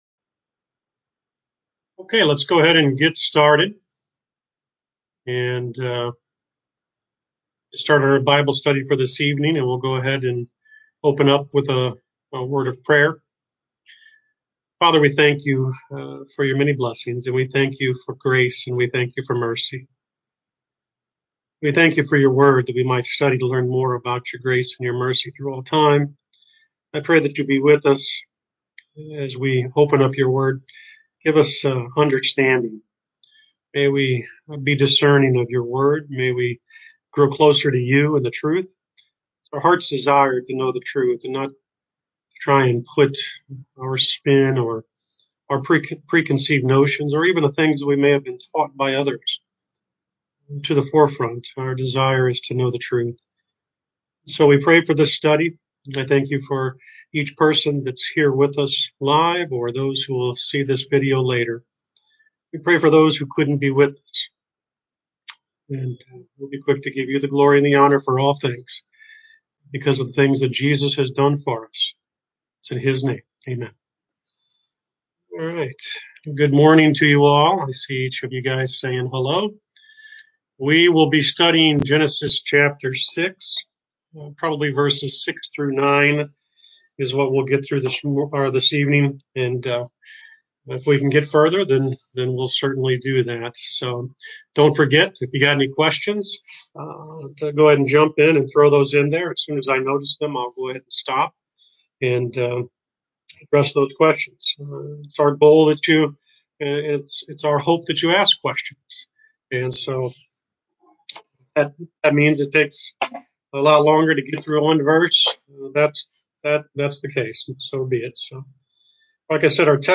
Bible Study: Genesis Ch 6:6-9